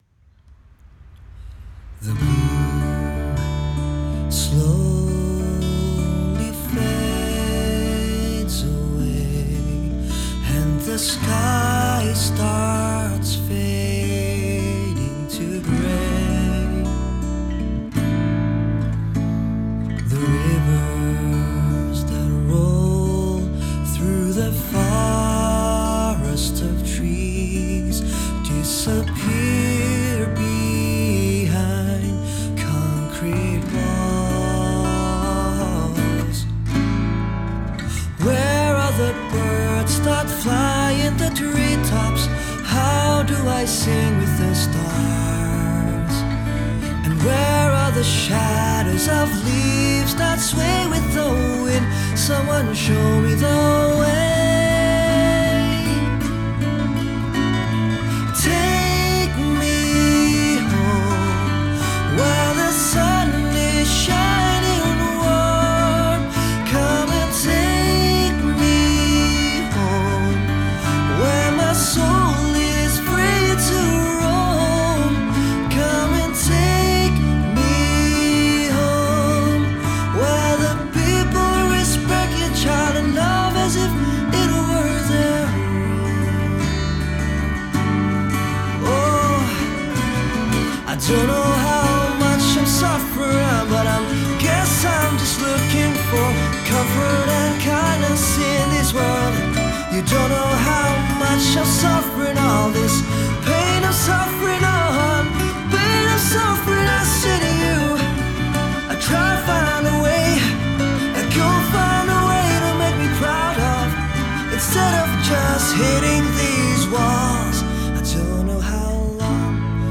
recorded at Dubai Marina